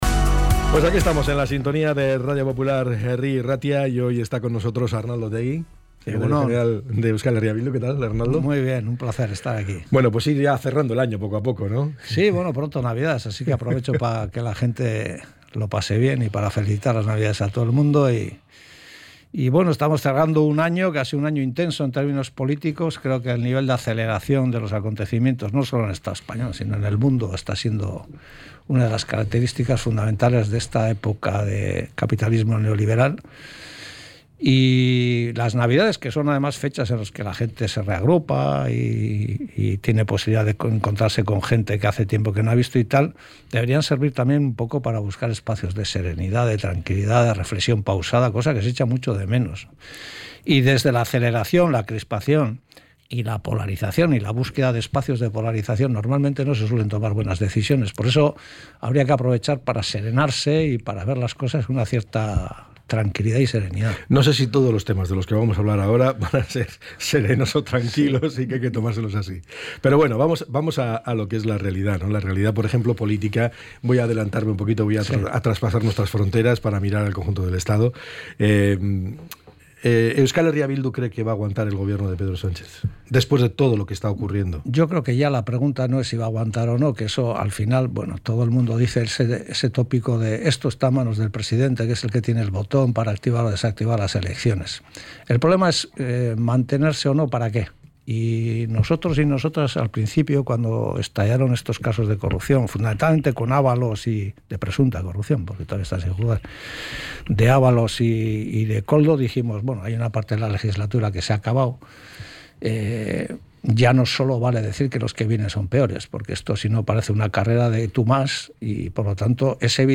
ENTREV.-OTEGI.mp3